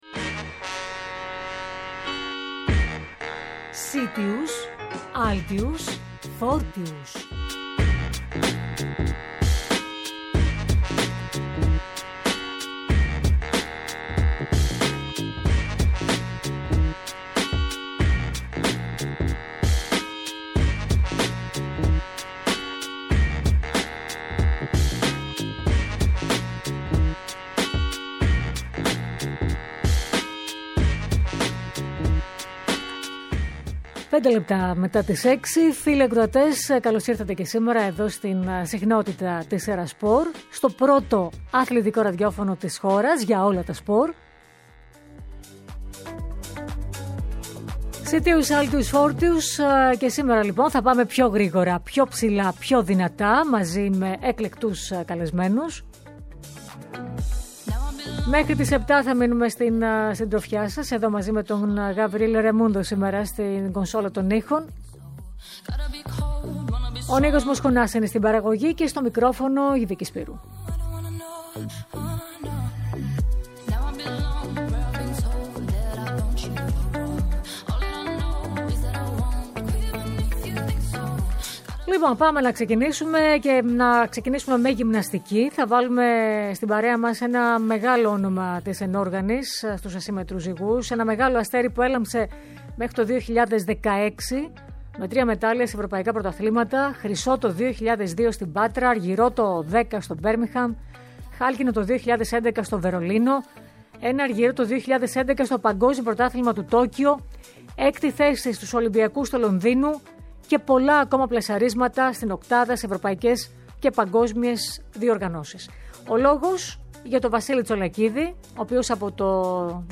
Κοντά μας σήμερα, ήταν ο ολυμπιονίκης, παγκόσμιος πρωταθλητής και πρωταθλητής Ευρώπης, στην ενόργανη γυμναστική και προπονητής πλέον, από το 2016, ο Βασίλης Τσολακίδης, ο οποίος μας μίλησε για το 4ο Διεθνές Διασυλλογικό τουρνουά Horizon cup, που θα γίνει το Σ/Κ στη Θεσσαλονίκη, αλλά και για το επίπεδο της γυμναστικής στην Ελλάδα.